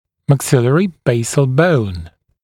[mæk’sɪlərɪ ‘beɪsl bəun][мэк’силэри ‘бэйсл боун]базис верхней челюсти